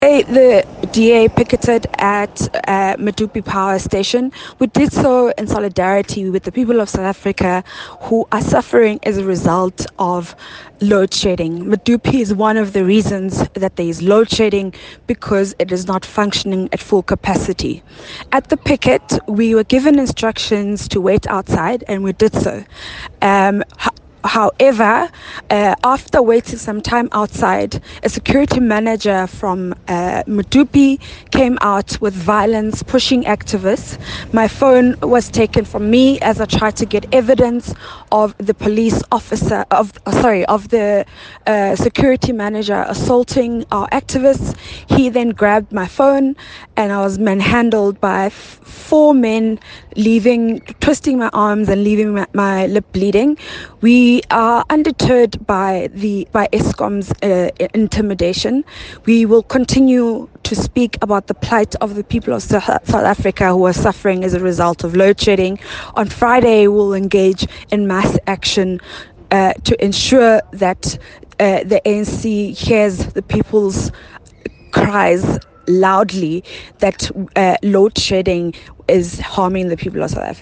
Issued by Phumzile Van Damme – DA Spokesperson on Corruption
Note to Editors: This statement follows a picket outside the Medupi Power Station in Limpopo.
soundbite by Van Damme.